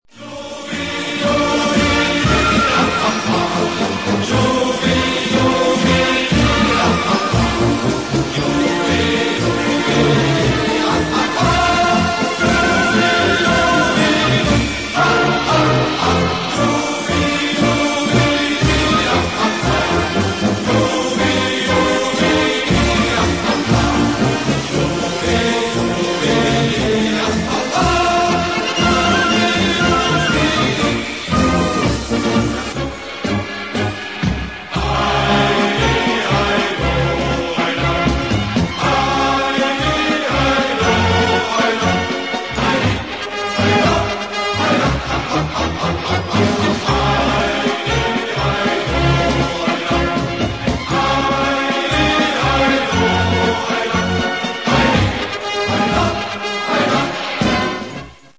Помните все те немыслимые рулады и переливы, которыми отличается её припев?